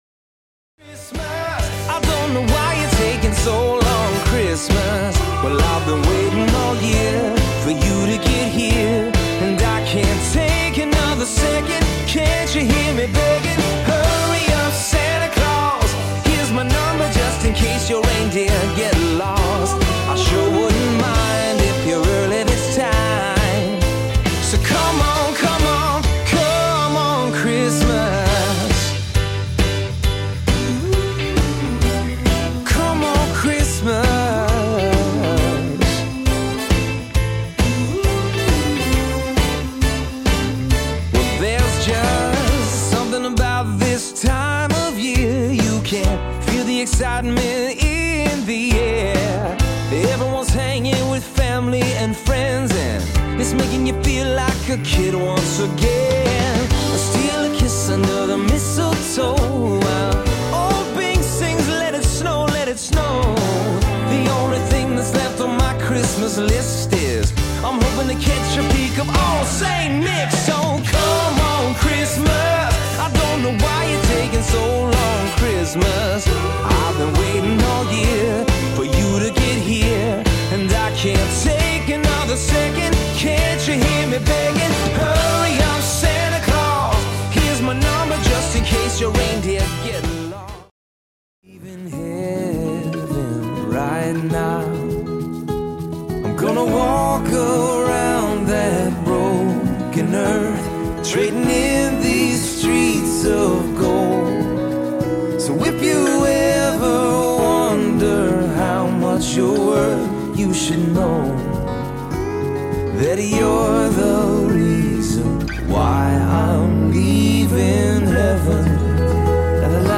comes decked out in a vibrant gospel arrangement